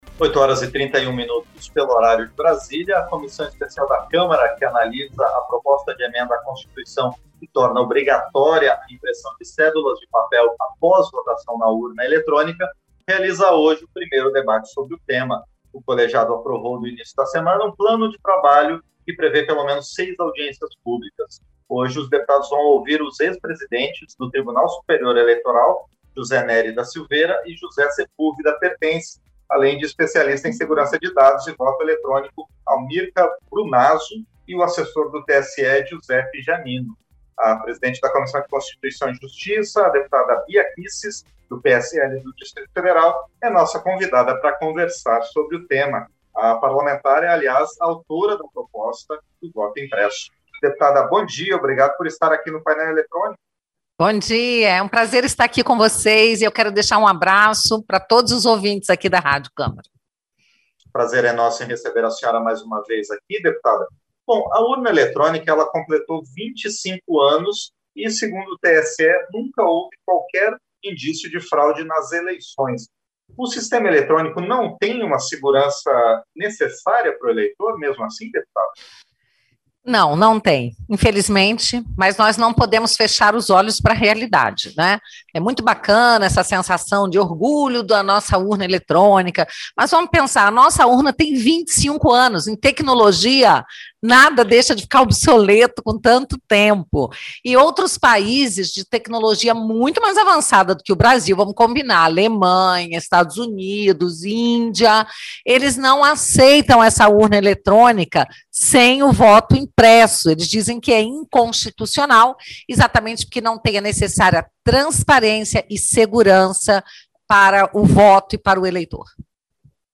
Entrevista - Dep. Bia Kicis (PSL-DF)